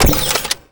sci-fi_weapon_reload_04.wav